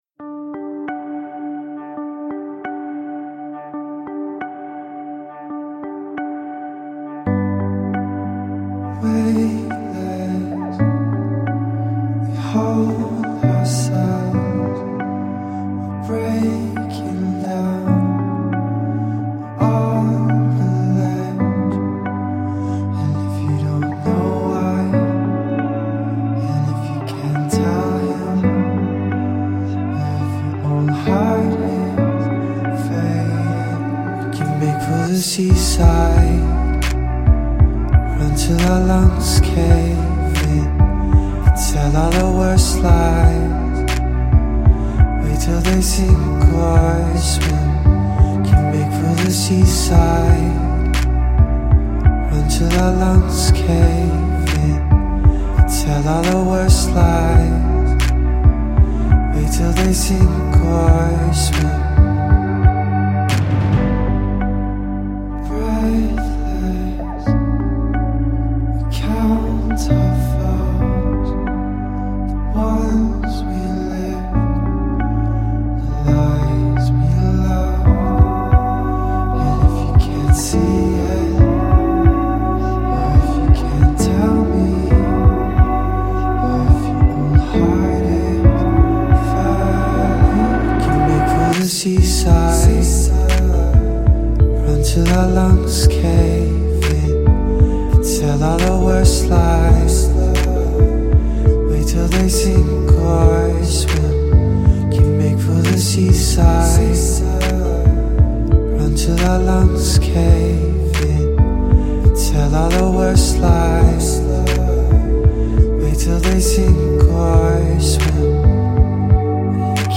Fusing dream-folk with lush electronic textures